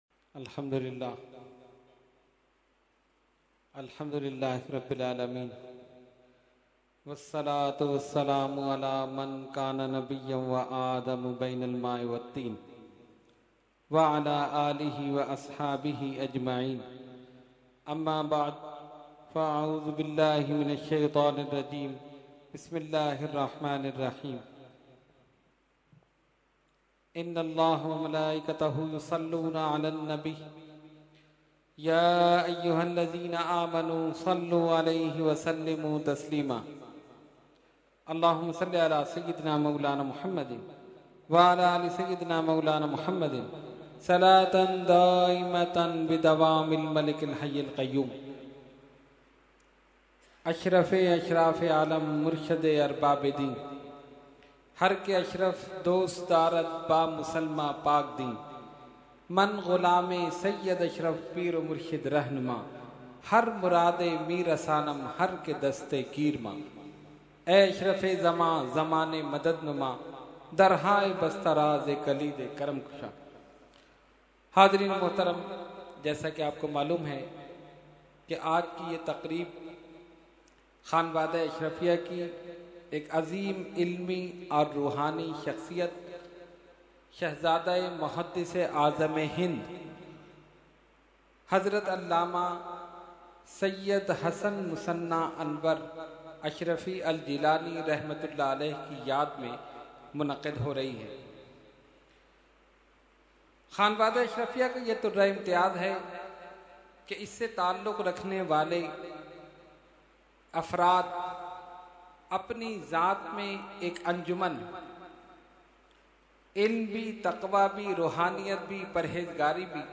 Category : Speech